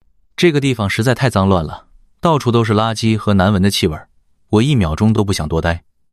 Neutral2.mp3